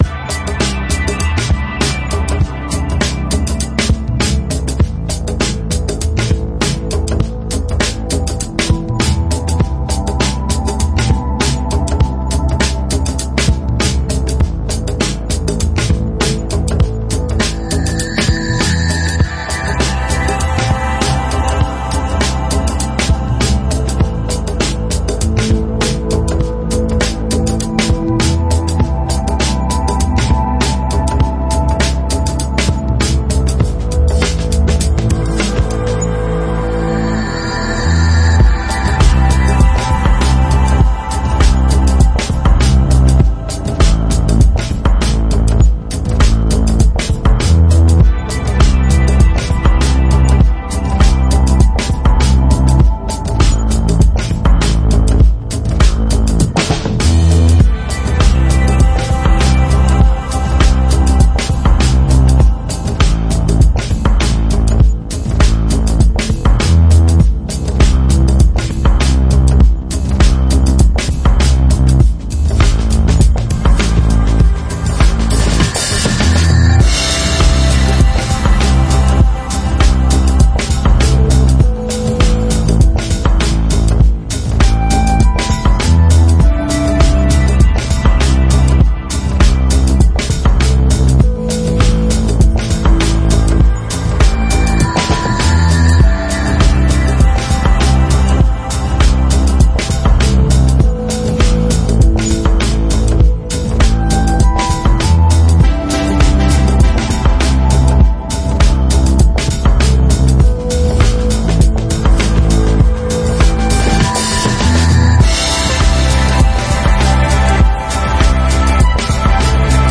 lounge compilation